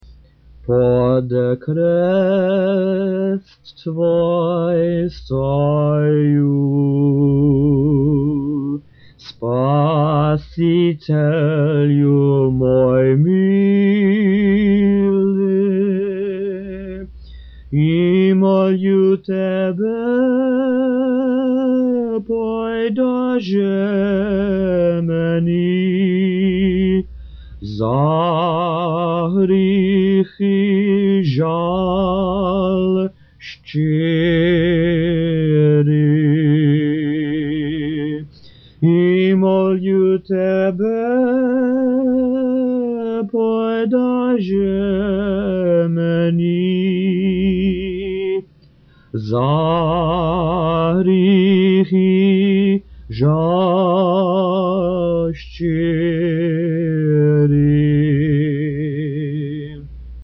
Spiritual songs > Hymns of the Great Fast >
This hymn is frequently sung before or after services on the weekdays of the Great Fast.